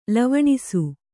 ♪ lavaṇisu